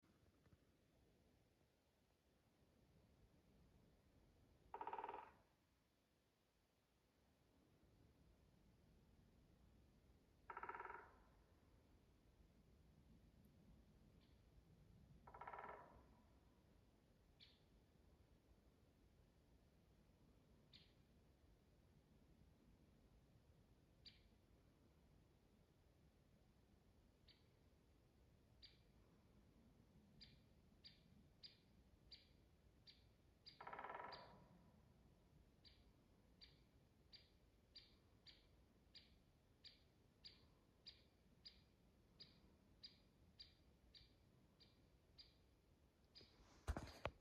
Great Spotted Woodpecker, Dendrocopos major
StatusVoice, calls heard
NotesVienā pusē takai bungo, otrā pusē atsaucas.